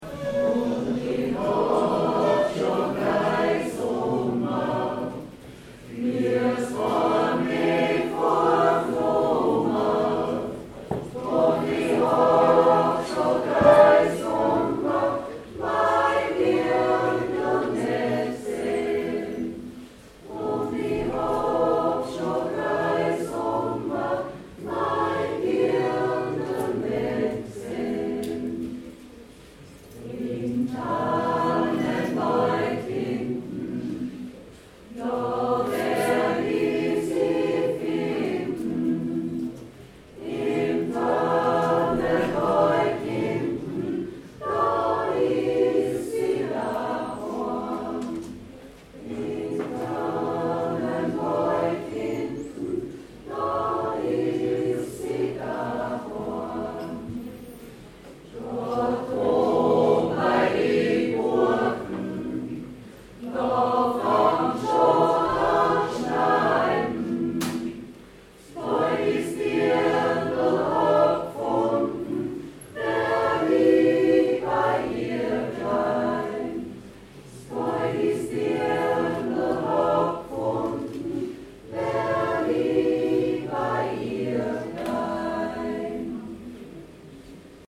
XANGSMEIEREI-Auftritt Tullnerbach 08.10.2016